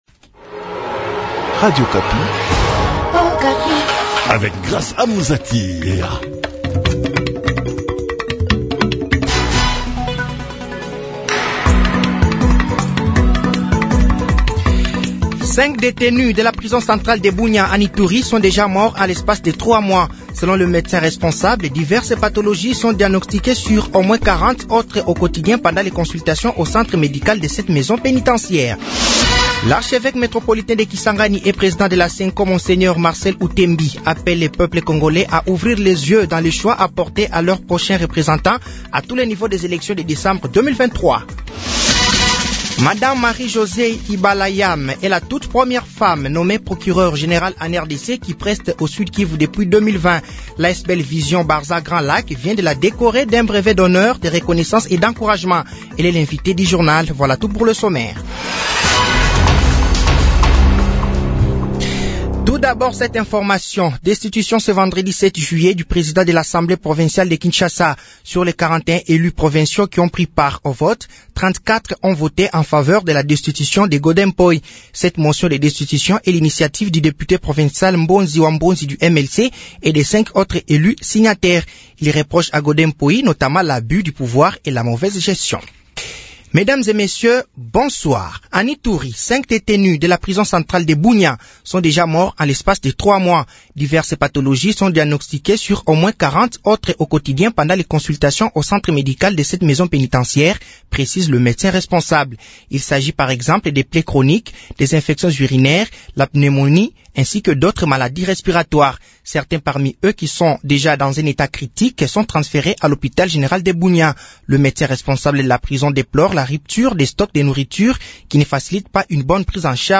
Journal français de 18h de ce vendredi 07/07/2023